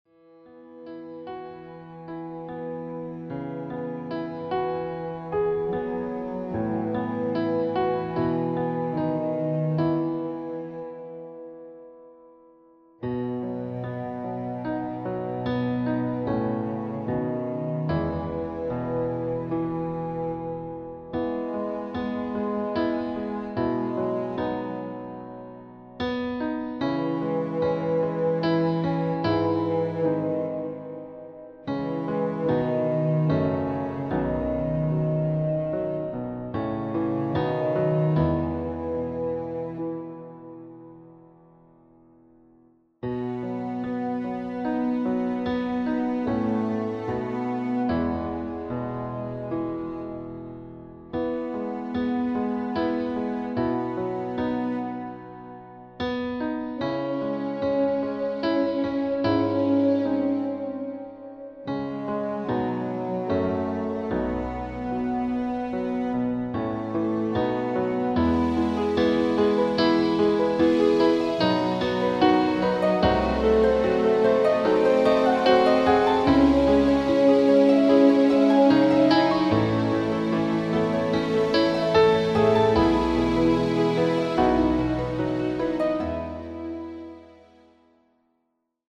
Tonart: C Dur , D Dur , E Dur
Art: Klavier Streicher Version
Das Instrumental beinhaltet NICHT die Leadstimme